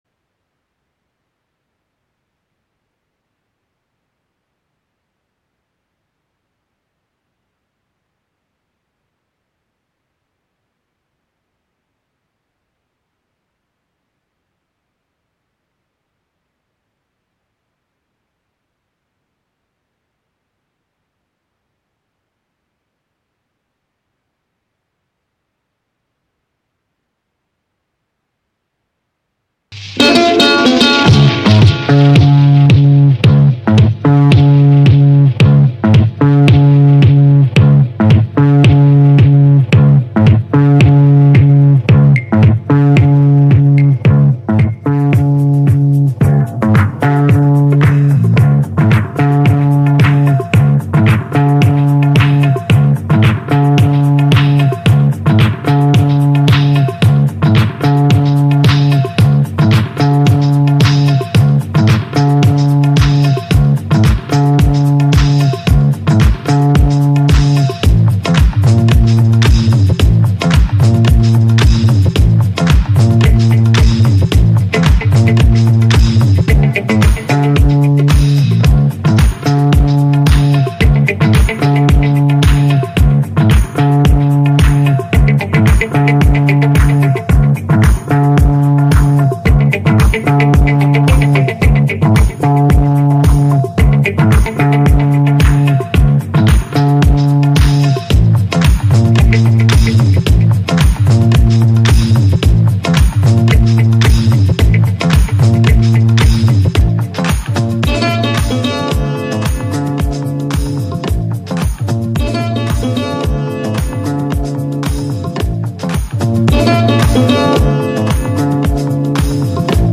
Broadcasting live from Catskill, NY.